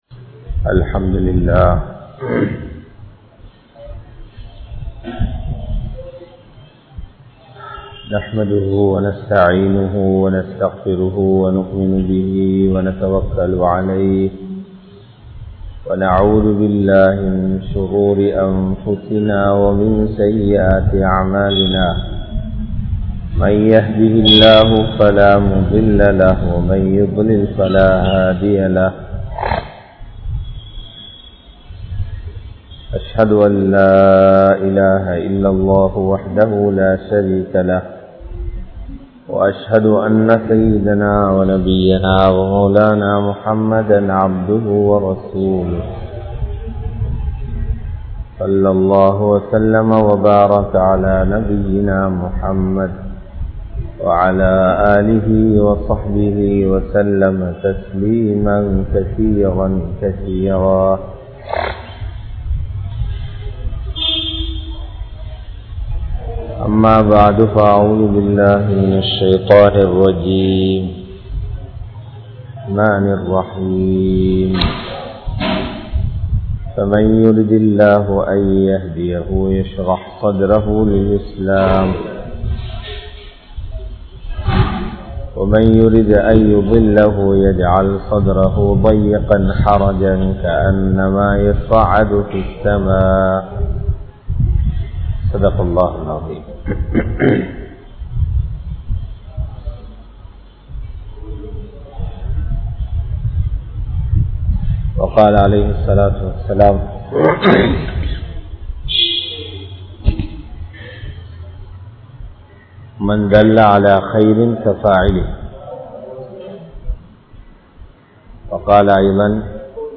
Ulamaakkal Sumanthulla Amaanithangal (உலமாக்கள் சுமந்துள்ள அமானிதங்கள்) | Audio Bayans | All Ceylon Muslim Youth Community | Addalaichenai
Jisthiyyah Jumuah Masjith